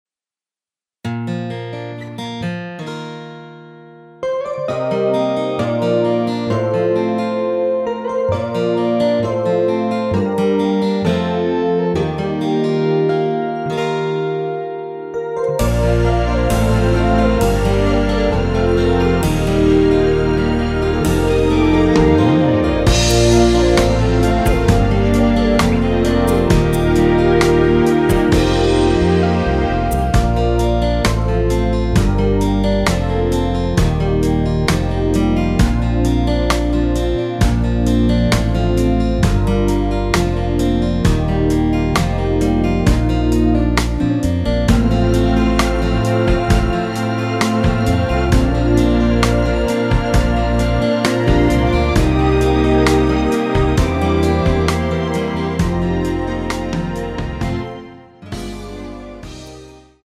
Bb
앞부분30초, 뒷부분30초씩 편집해서 올려 드리고 있습니다.